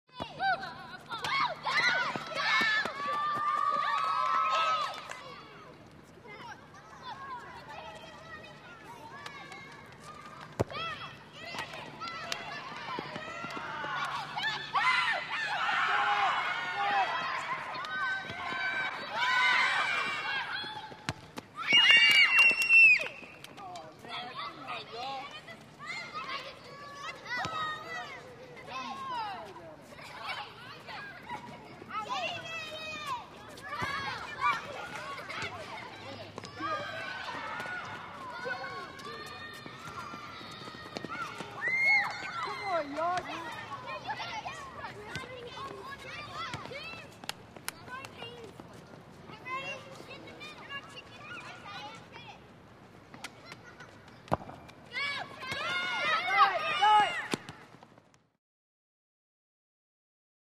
Звуки детского сада
Крики детей в процессе игр